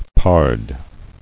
[pard]